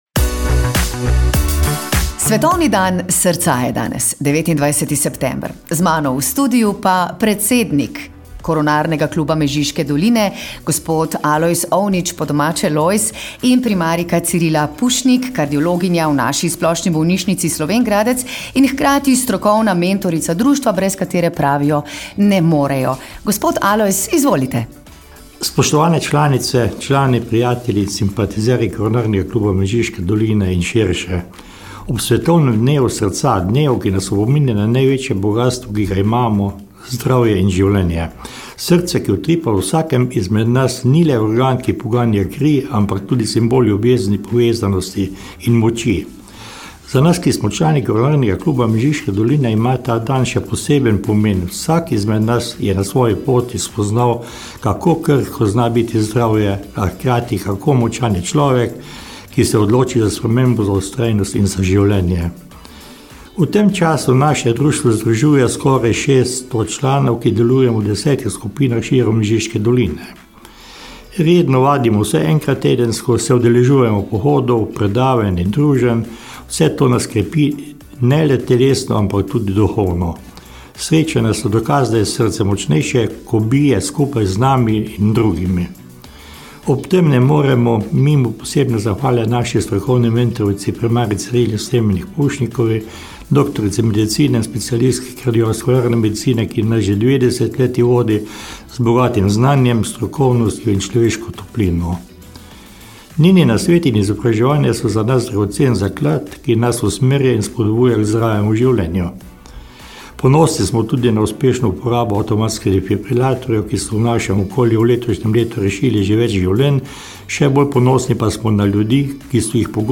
pogovor .mp3